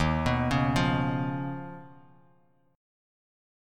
Listen to Eb7sus2#5 strummed